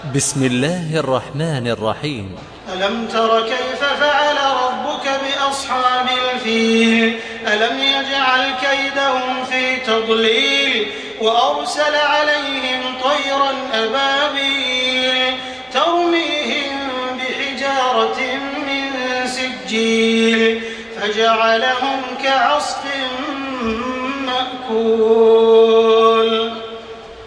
تحميل سورة الفيل بصوت تراويح الحرم المكي 1428